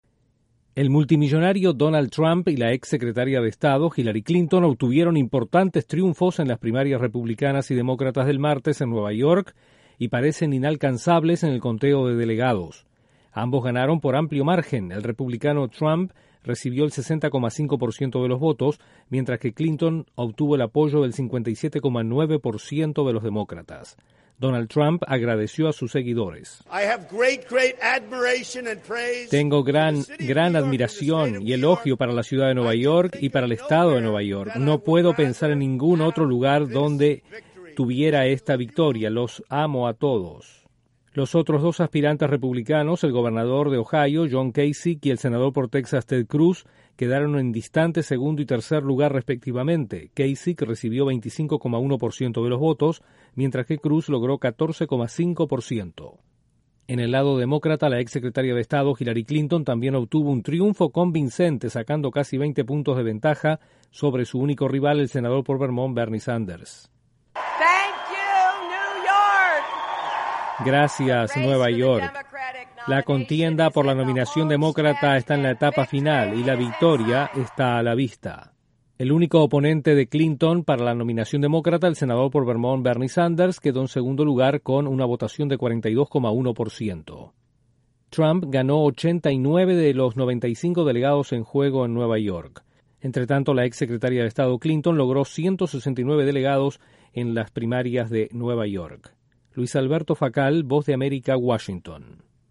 Donald Trump y Hillary Clinton lograron amplias victorias en las elecciones primarias de Nueva York. Desde la Voz de América en Washington informa